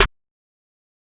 EN - Metro (Snare).wav